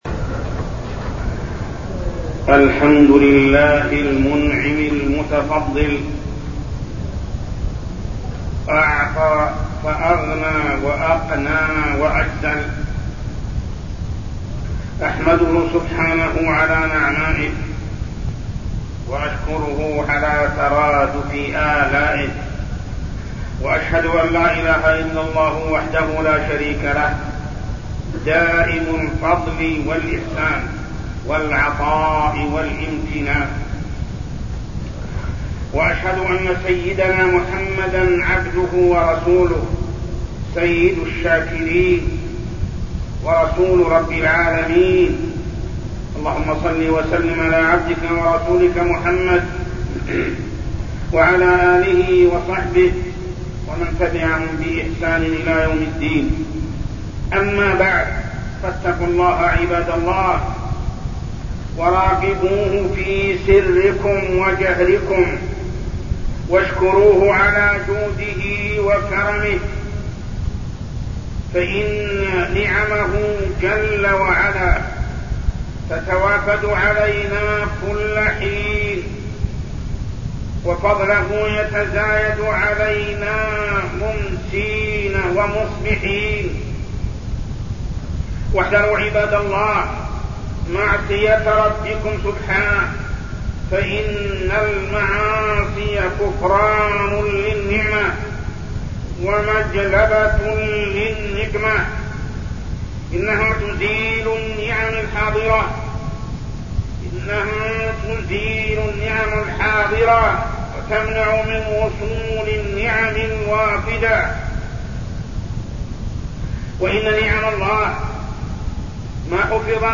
تاريخ النشر ١٥ جمادى الآخرة ١٤١٠ هـ المكان: المسجد الحرام الشيخ: محمد بن عبد الله السبيل محمد بن عبد الله السبيل الحذر من المعاصي